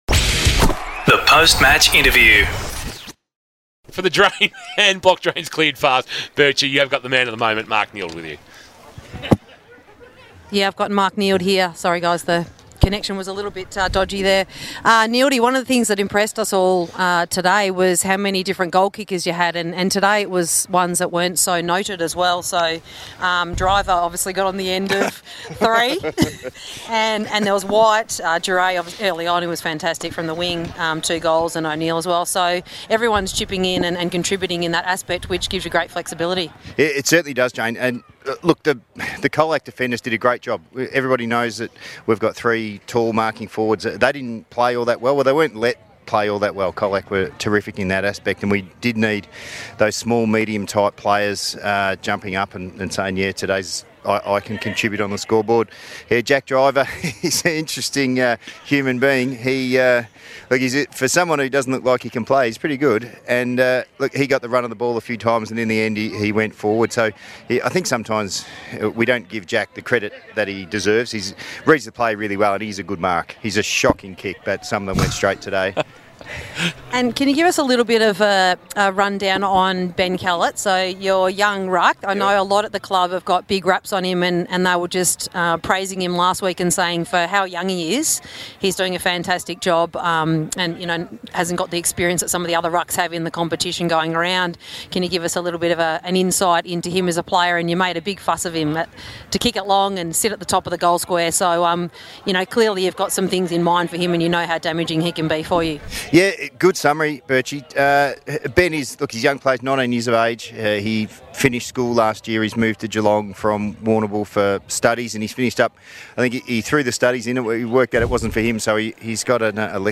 2023 - GFNL - Qualifying Final - COLAC vs. SOUTH BARWON: Post-match interview